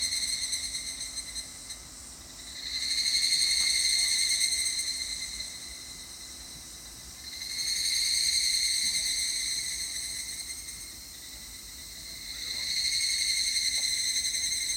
蜩（ひぐらし）の鳴き声
山の中のオーベルジュで行われた、
夕方、涼しげな蜩の鳴き声が・・・